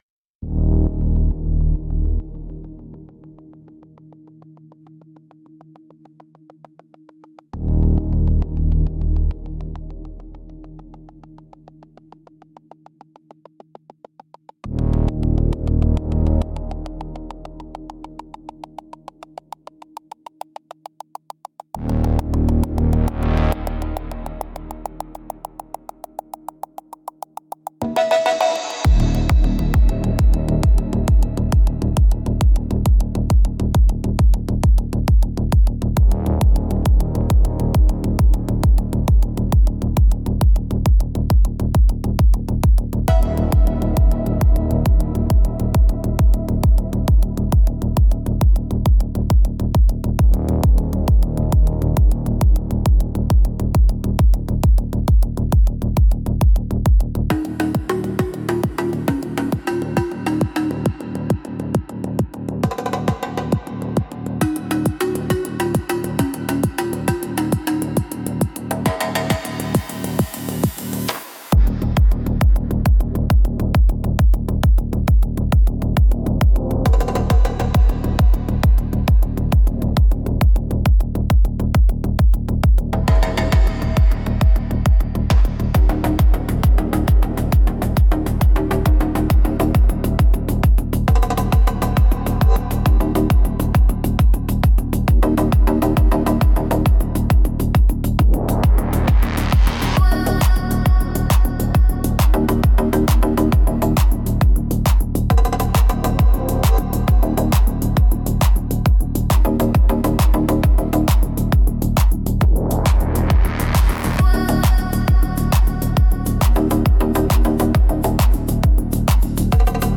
Instrumentals - Hunter's Code